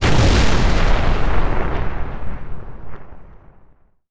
ZombieSkill_SFX
sfx_skill 14_2.wav